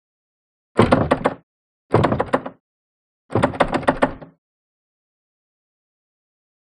Door Knob; Rattle; Rattle Locked Door Knob